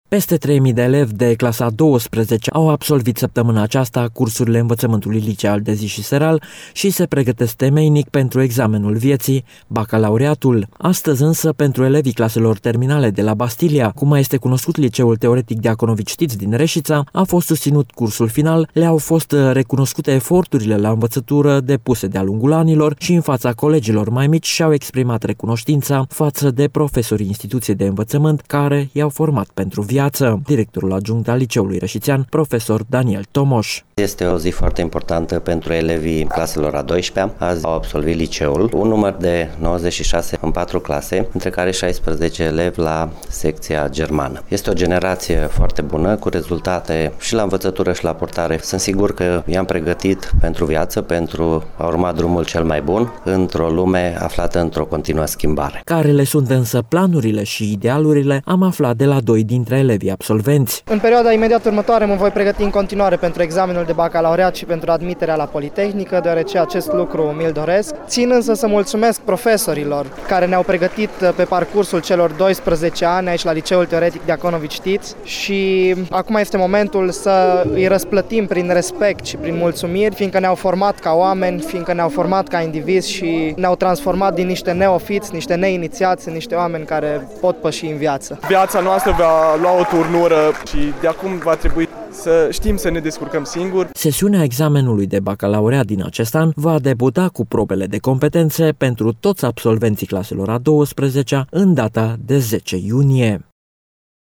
Acestea le-au fost înmânate în cadrul unui eveniment festiv desfăşurat în curtea instituţiei de învăţământ de către unul dintre consultanţii de specialitate pentru România ai Centralei pentru învăţământul în străinătate.